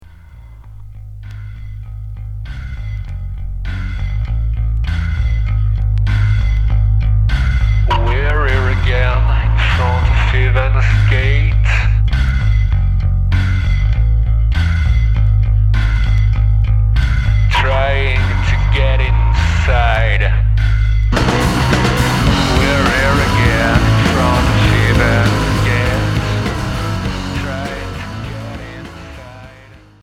Noisy pop